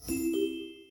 match-join.ogg